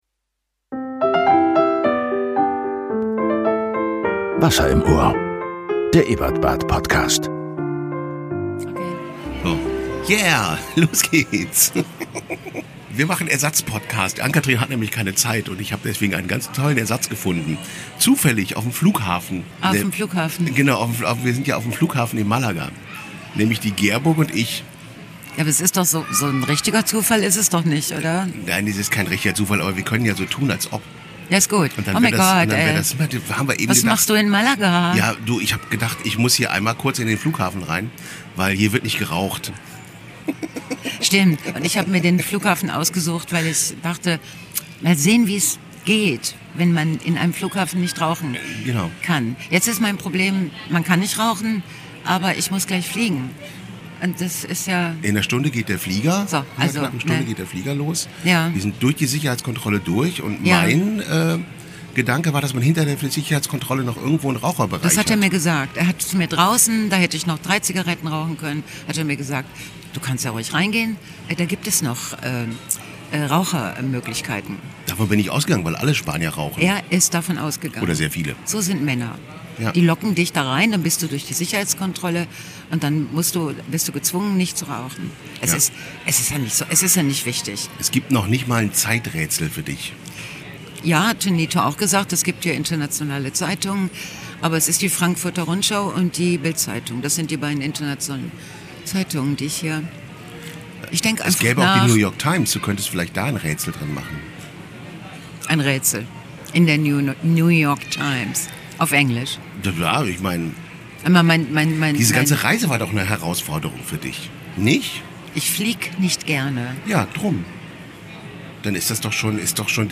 Im Interview
Mit am Mikro: Gerburg Jahnke. Zufällig getroffen am Flughafen Malaga.